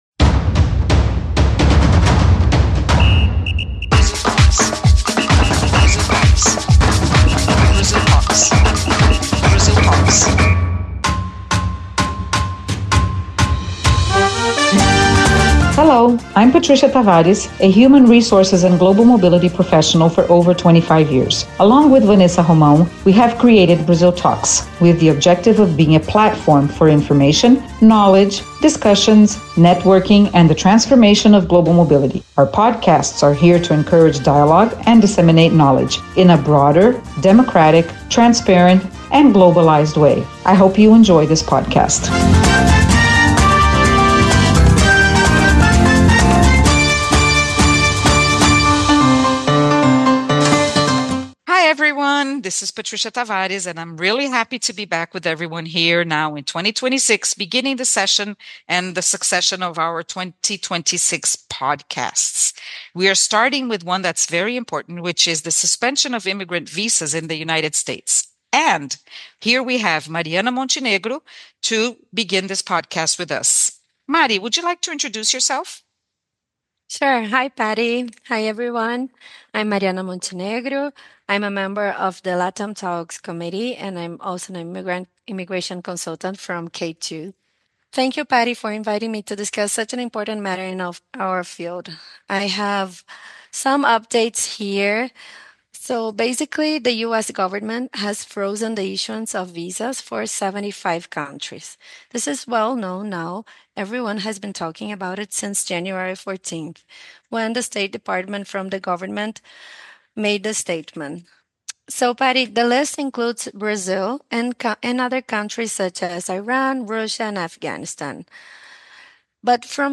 a brief chat about the changes to the United States immigrant visa.